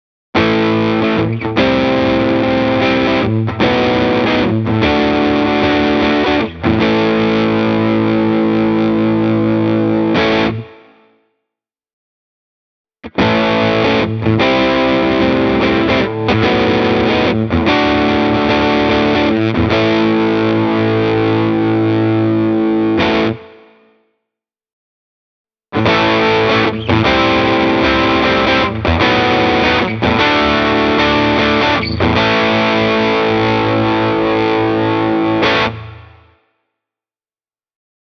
EMG seem to use the expression ”vintage” more interms of a tonal reference that with regard to a pickup’s output level, judging by this very punchy set of Metal Works-humbuckers.
Depending on the rest of your signal chain, you may be forced to turn the guitar’s volume control down to keep your signal clean:
But for overdriven and distorted use these powerful pickups have just what it takes: